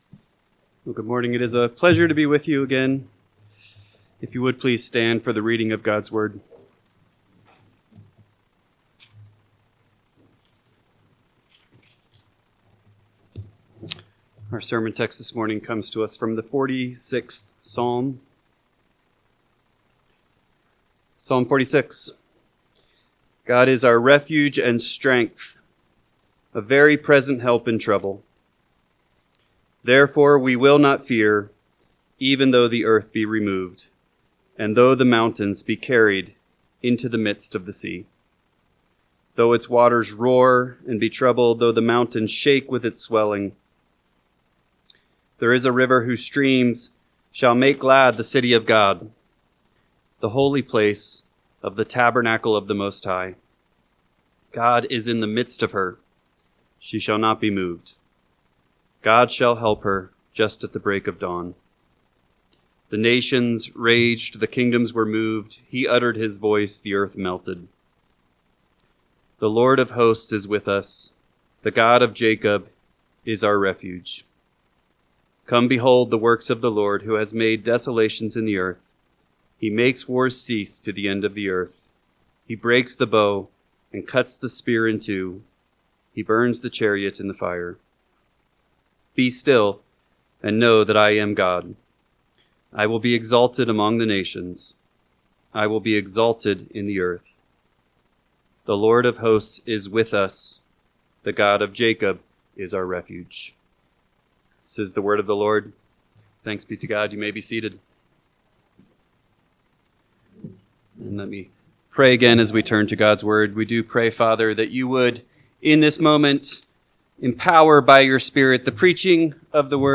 AM Sermon – 8/24/2025 – Psalm 46 – Northwoods Sermons